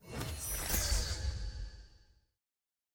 sfx-s17-collection-unlock-highlight.ogg